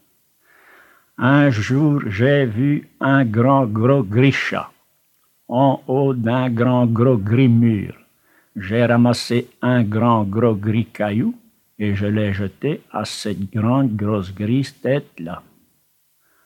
Genre : chant
Type : chanson à poter
Interprète(s) : Anonyme (homme)
Support : bande magnétique